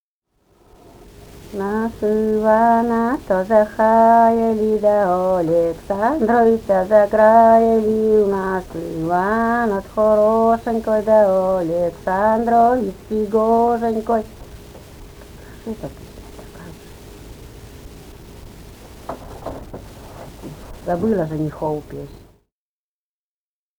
«В нас Ивана-то захаяли» (свадебная).